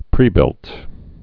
(prēbĭlt)